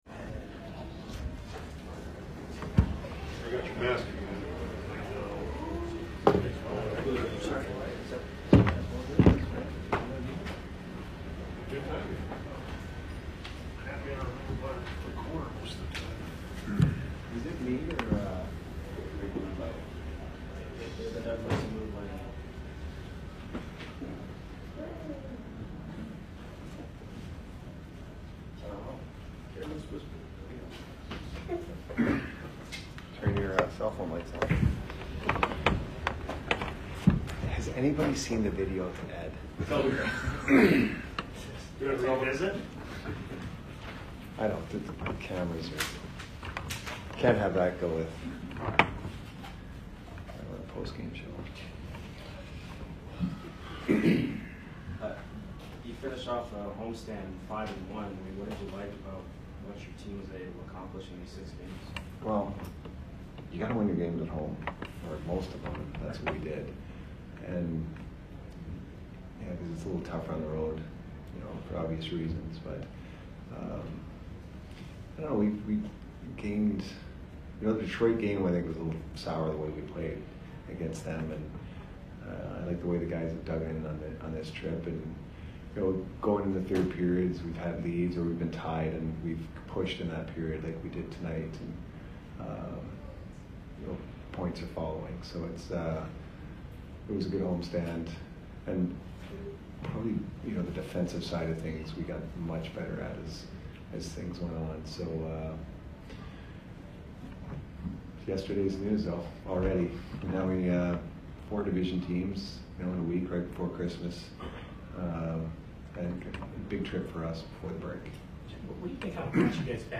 Head Coach Jon Cooper Post Game 12/15/22 vs CBJ